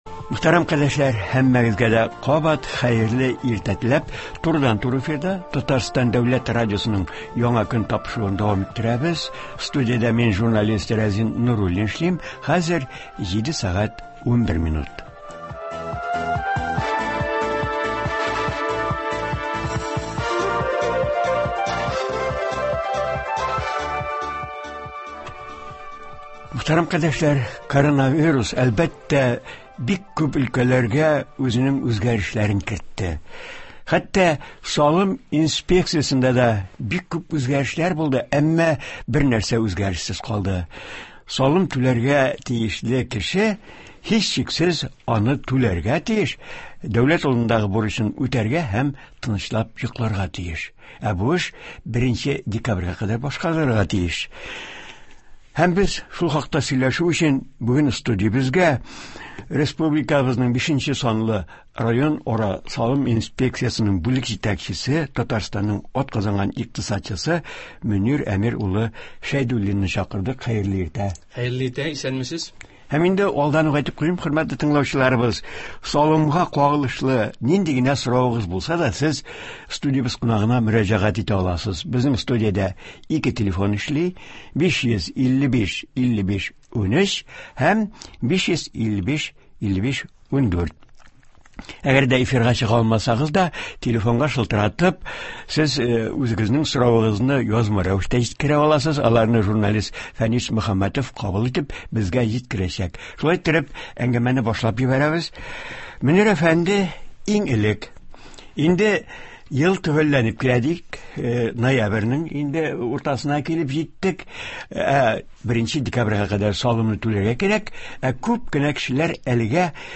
Бу үзгәрешләр хакында турыдан-туры эфирда